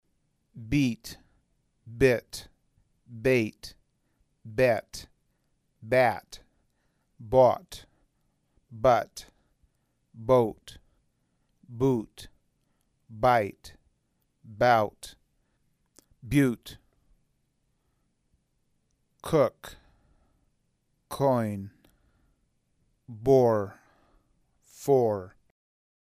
Ejemplos de los fonemas vocálicos del inglés.